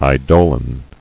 [ei·do·lon]